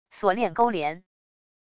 锁链勾连wav音频